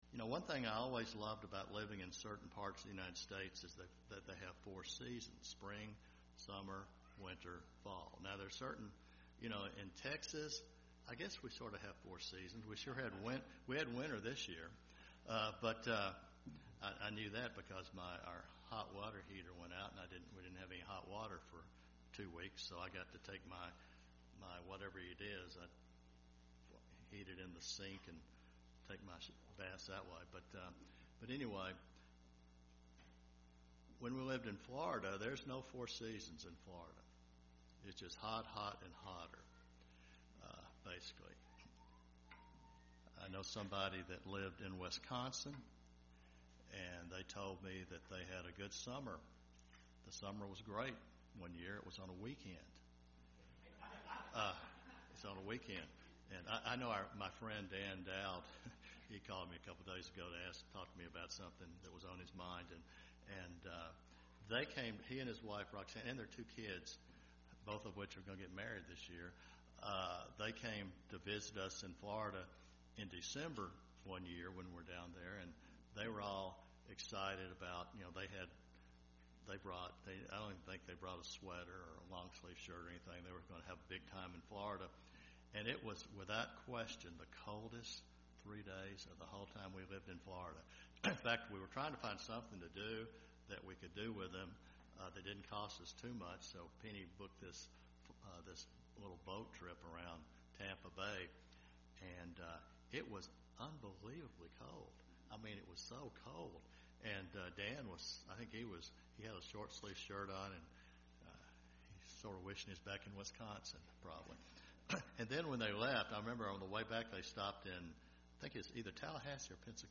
Sermons
Given in Dallas, TX